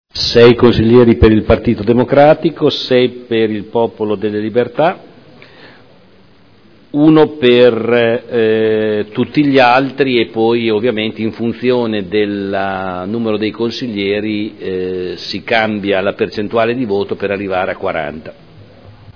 Seduta del 12 dicembre Commissione consiliare permanente Controllo e Garanzia - Modifica